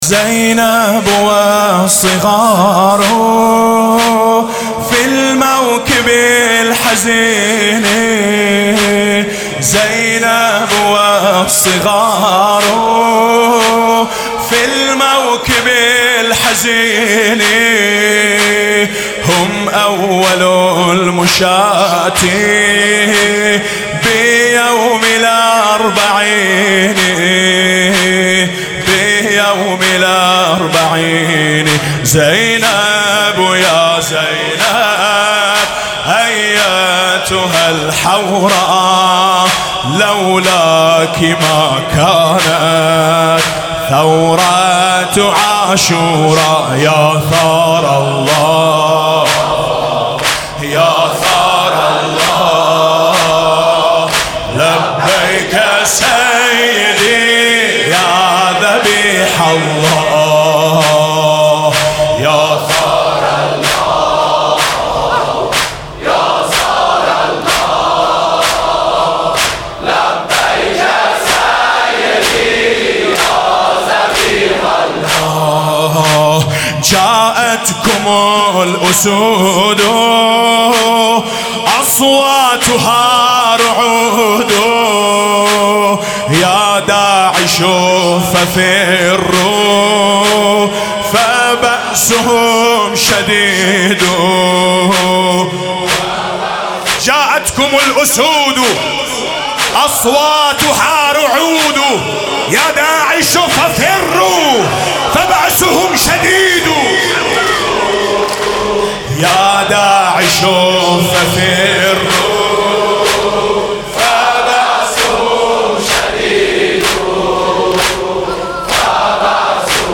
لطميات حسينية لطميات محرم